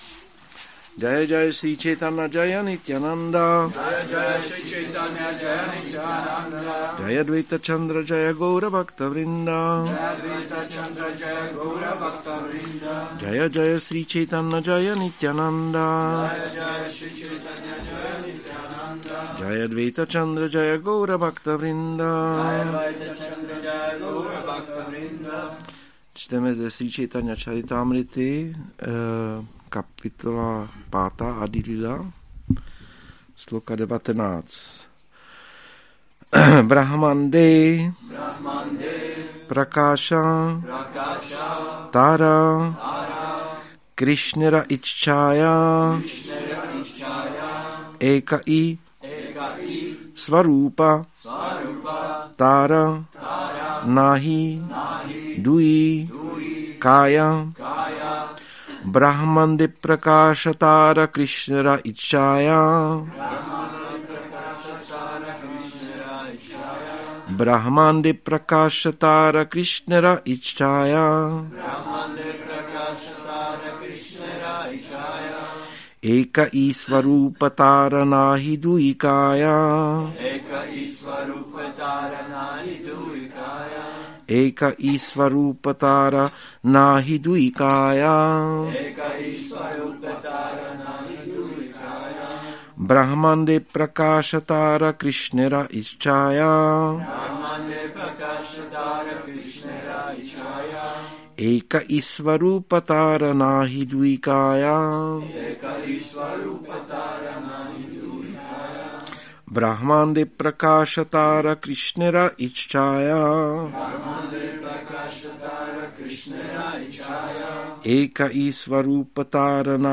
Přednáška CC-ADI-5.19 – Šrí Šrí Nitái Navadvípačandra mandir